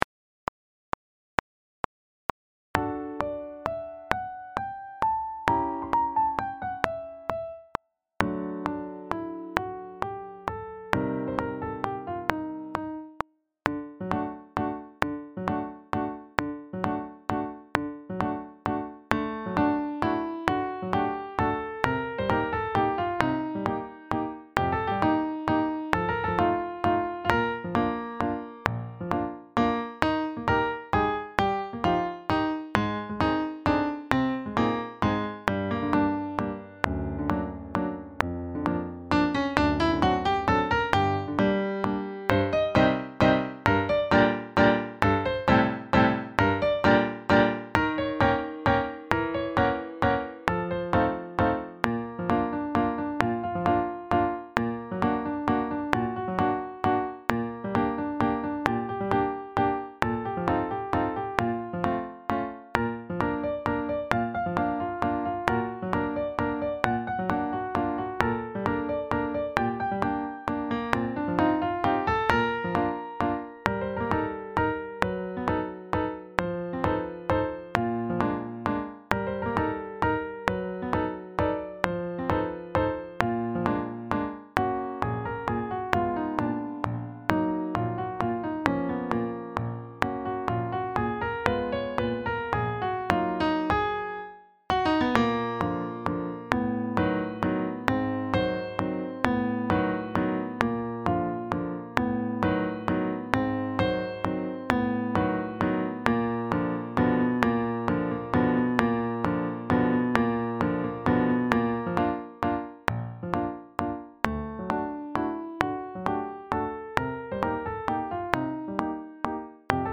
A + pianoDuration:
Backing track
171-4-seguidillasolo-backing-track.mp3